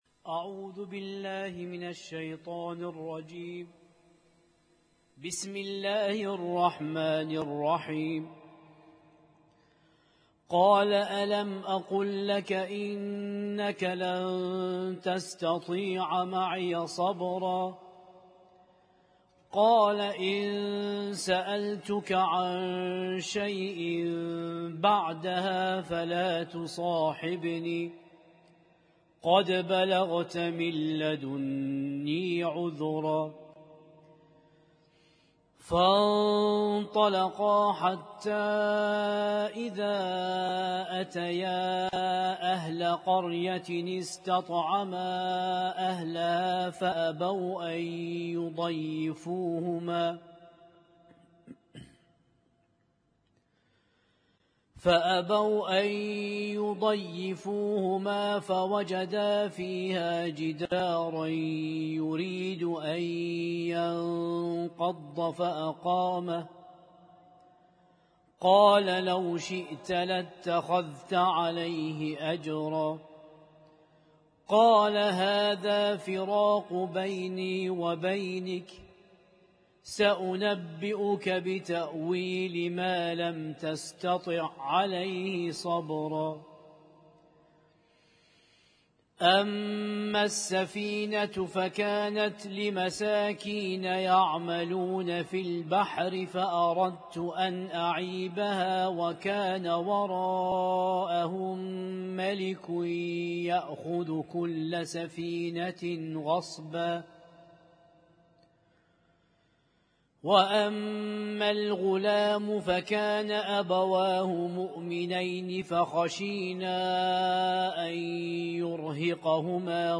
Husainyt Alnoor Rumaithiya Kuwait
المـكتبة الصــوتيه >> القرآن الكريم